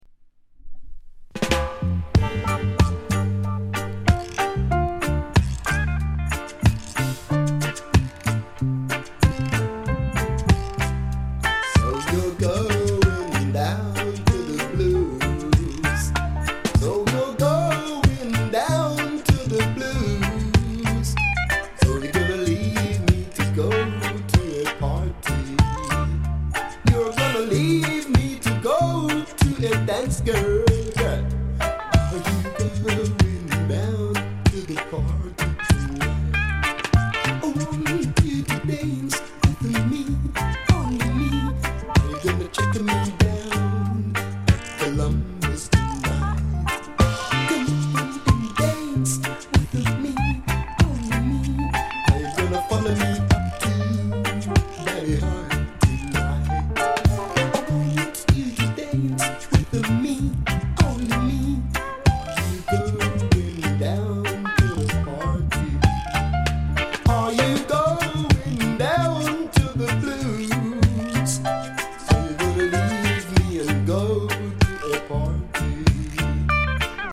ソウルフル *